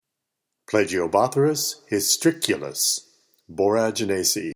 Pronunciation/Pronunciación:
Pla-gi-o-bó-thrys hys-trí-cu-lus